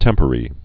(tĕmpə-rē)